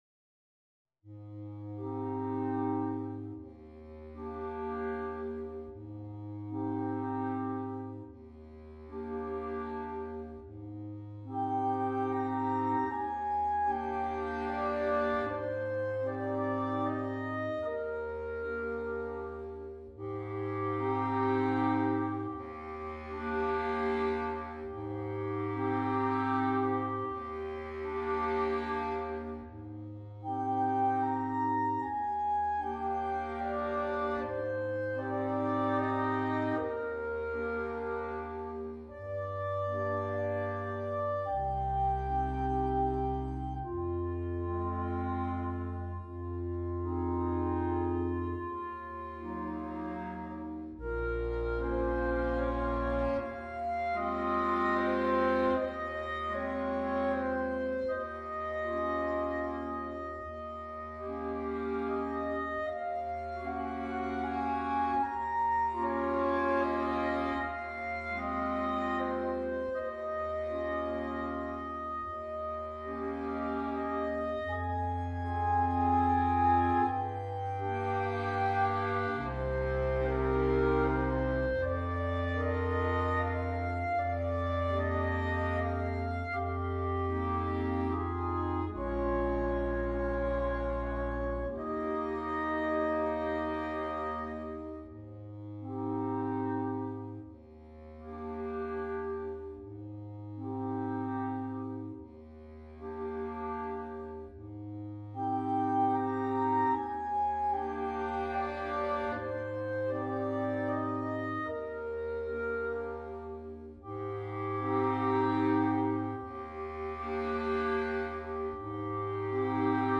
Nella trascrizione per quintetto di clarinetti